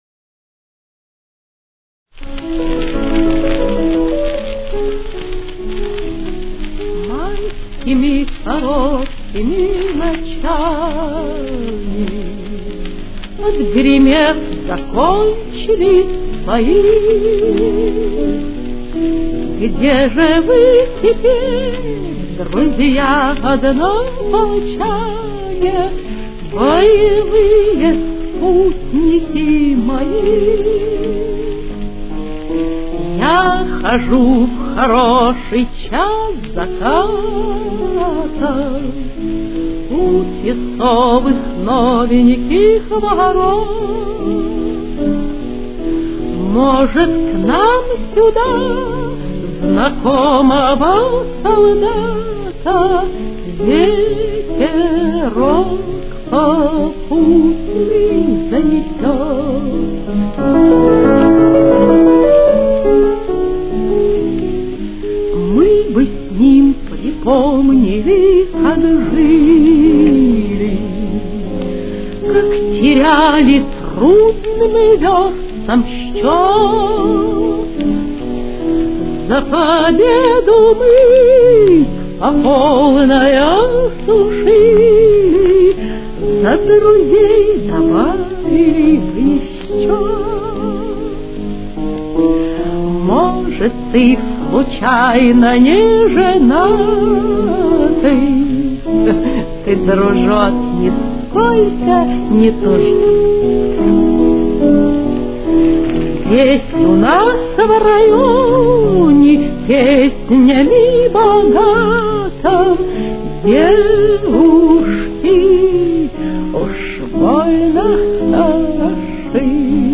До-диез минор. Темп: 76.